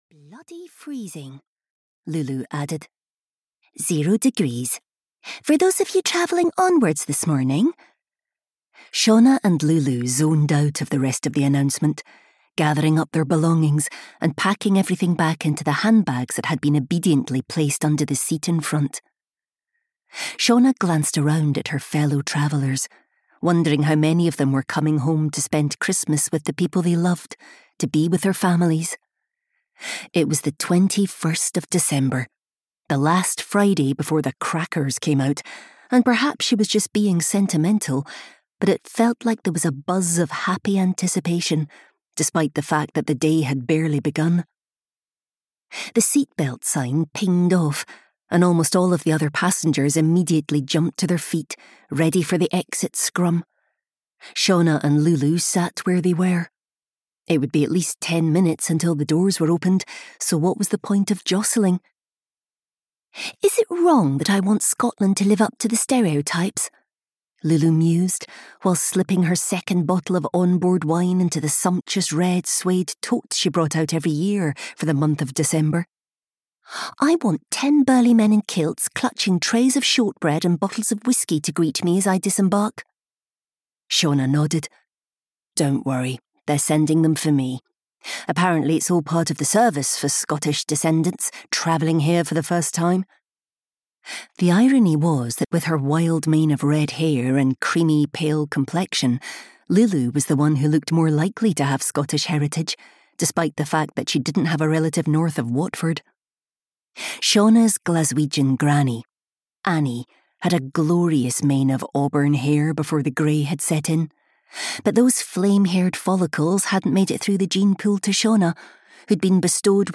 Audiobook Another Day in Winter (EN), written by Shari Low.
Ukázka z knihy